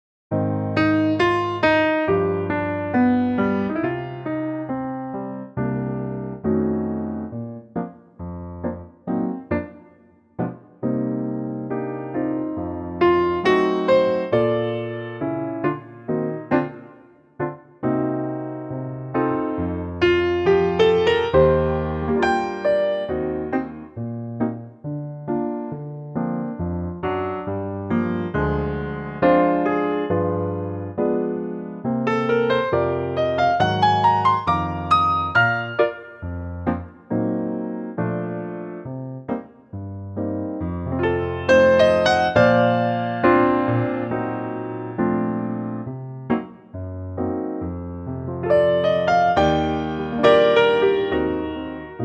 key - Bb - vocal range - C to D
a full tone lower.